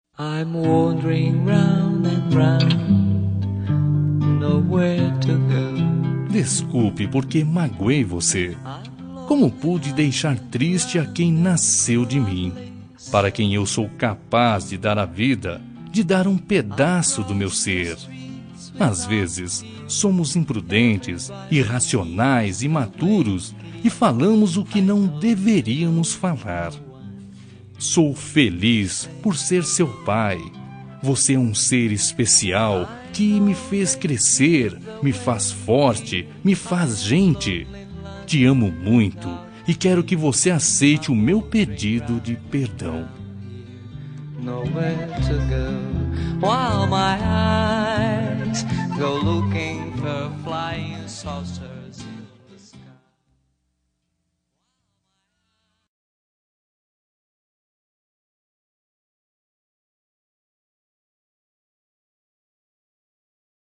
Reconciliação Familiar – Voz Masculina – Cód: 088741 – Filho (a)